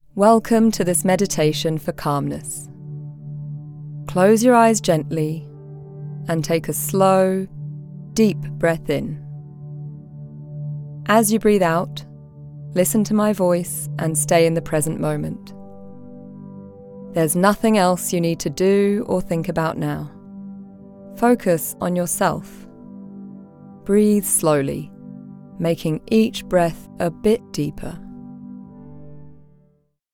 Meditation for Calmness – Locução feminina em inglês para relaxamento
Macmillan_meditation_com_trilha.mp3